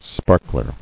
[spar·kler]